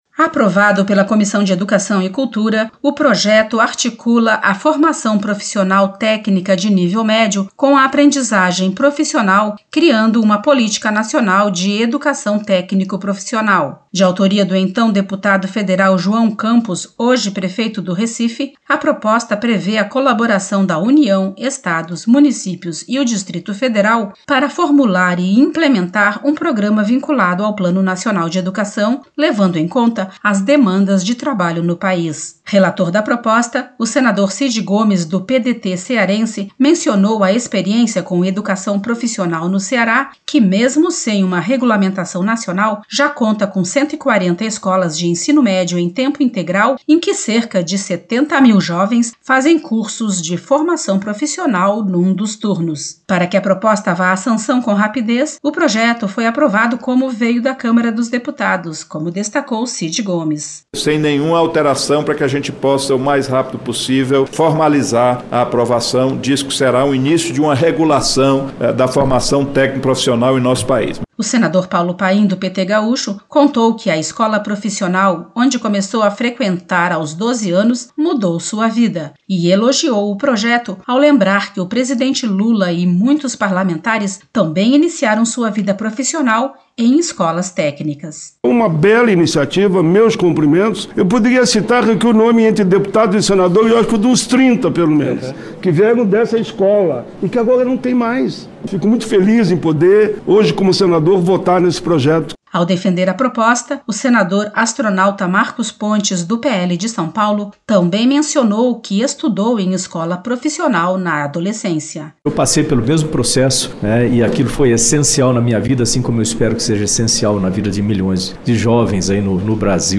Paulo Paim (PT-RS) e Astronauta Marcos Pontes (PL-SP) contaram que iniciaram sua vida profissional em escolas técnico-profissionalizantes. O texto segue para votação no Plenário.